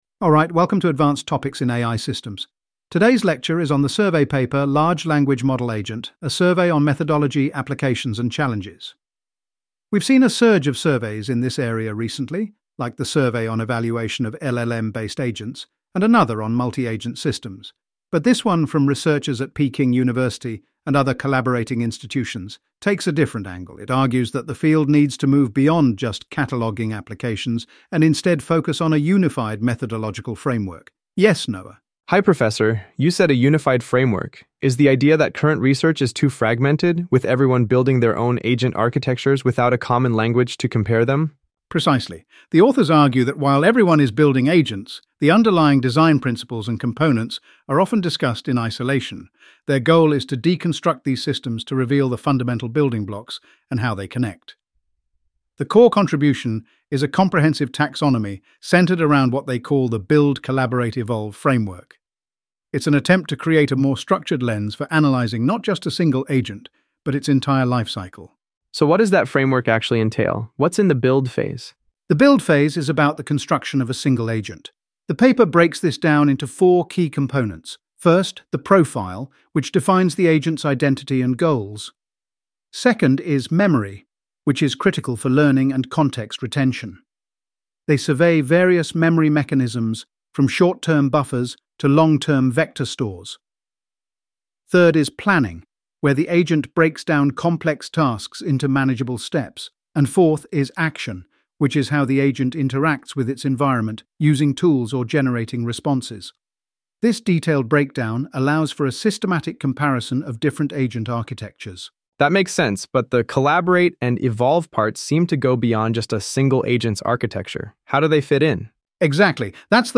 AI Audio Lecture + Q&A 0:00